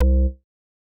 MOO Bass C1.wav